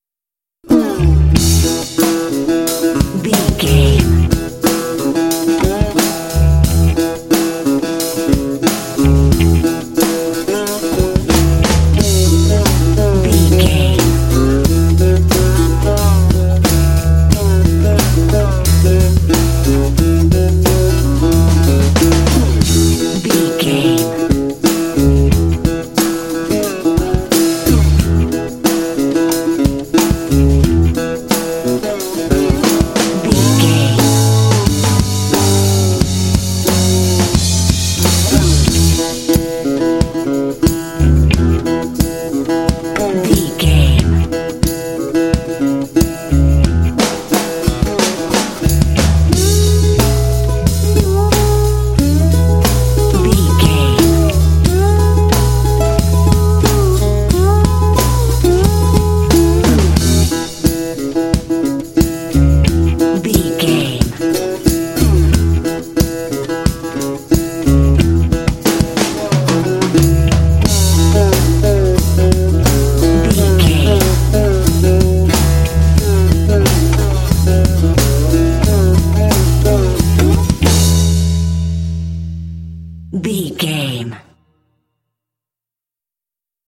Uplifting
Mixolydian
playful
cheerful/happy
acoustic guitar
percussion
bass guitar
drums
country
bluegrass